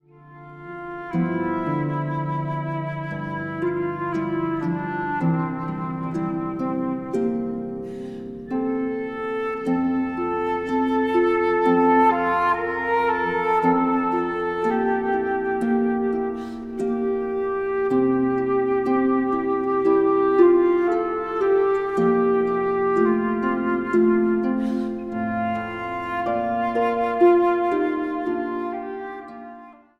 Prachtige instrumentale muziek
fluiten en whistles
Instrumentaal | Dwarsfluit
Instrumentaal | Harp